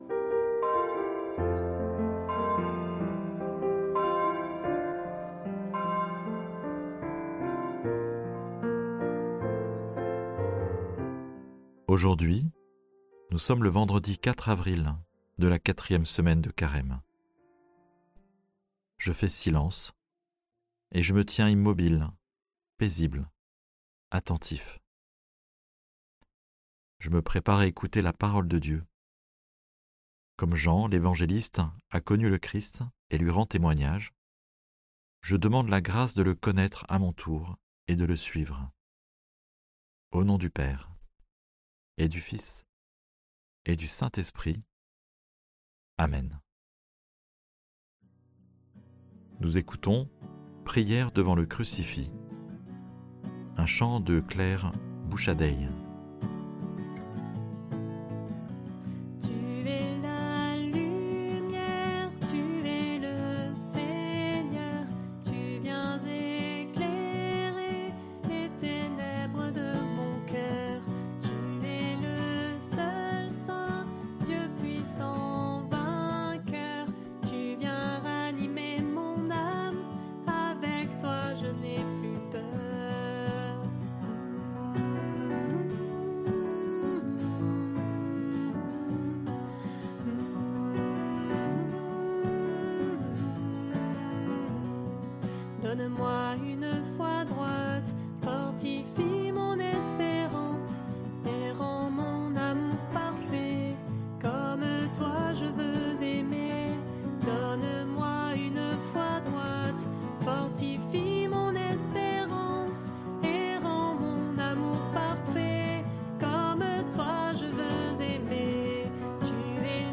Prière audio avec l'évangile du jour - Prie en Chemin